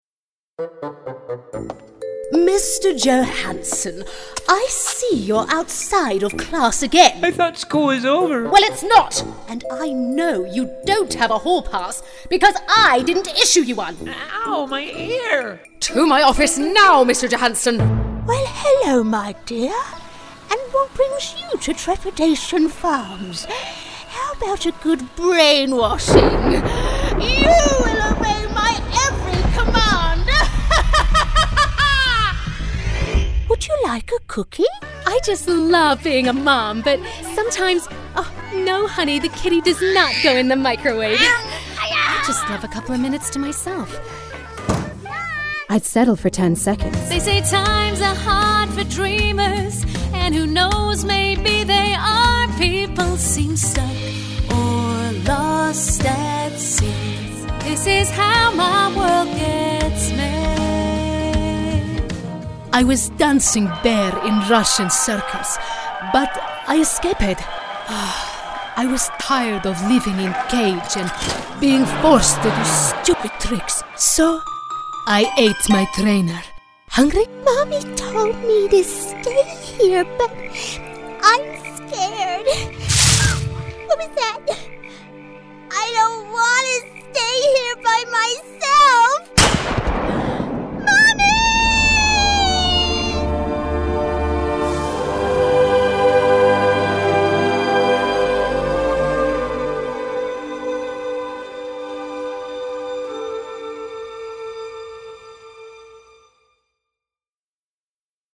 Inglés (Británico)
Comercial, Cool, Versátil, Cálida
Comercial